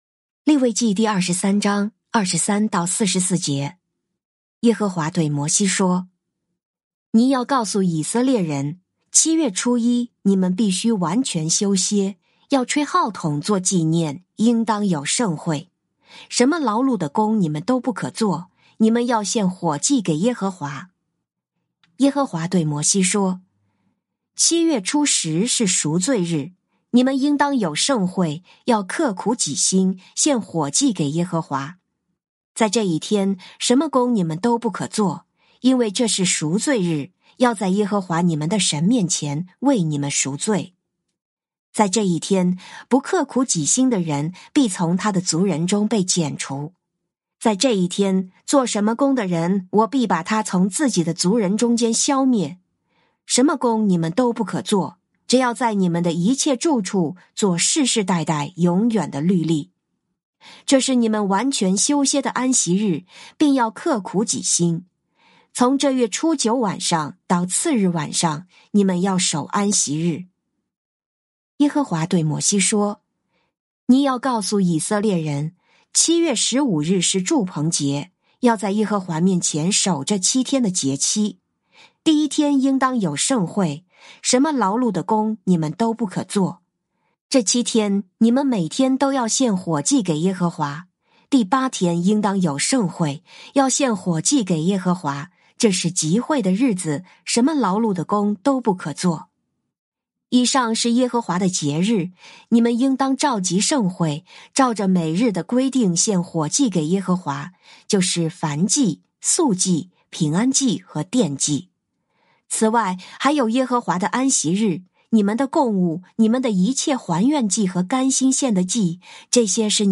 靈修分享：利23章23-44節「刻苦己心」
「天父爸爸說話網」是由北美前進教會Forward Church 所製作的多單元基督教靈修音頻節目。